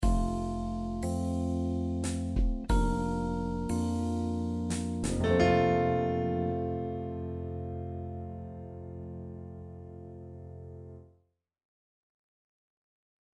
3’17 BPM: 90 Description: Relaxed, cocktail music Mood
Piano, strings Genre: Easy listening Composer/Artist
Plays short start of the track